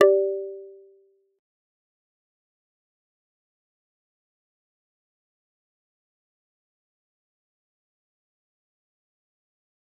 G_Kalimba-G4-mf.wav